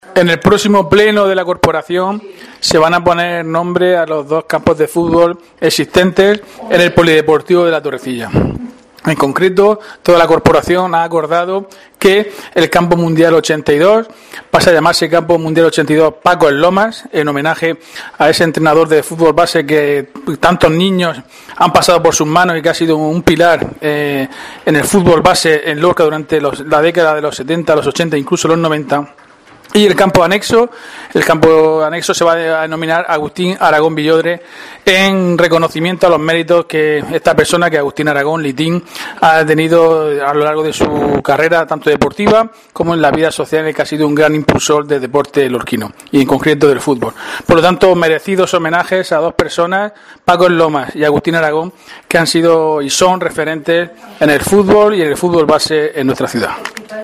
Diego José Mateos, alcalde de Lorca sobre Mundial 82 y anexo